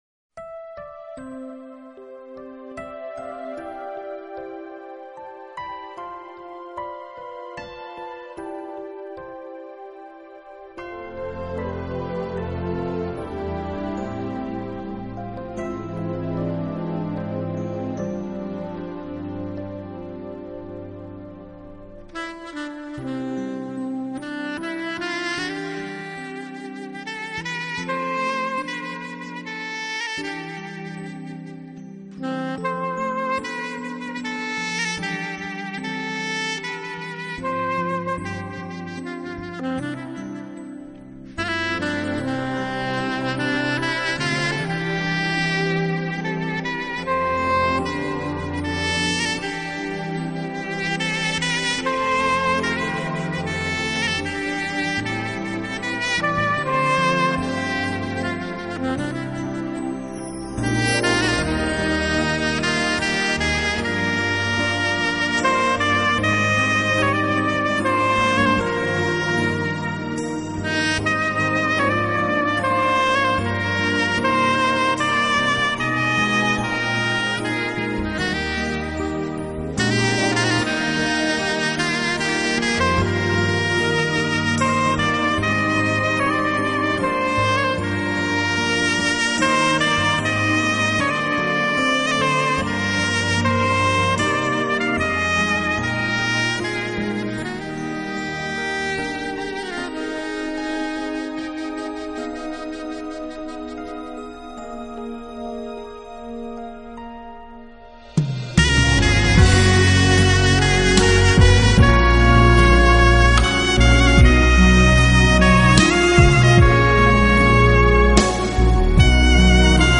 高超华丽的演奏技巧、多元抒情的曲风，这就是优雅、华丽而独特的“基”